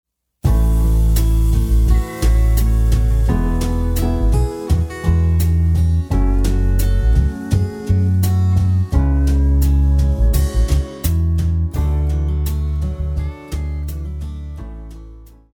Classical
French Horn
Band
Etude,Course Material,Classical Music
Only backing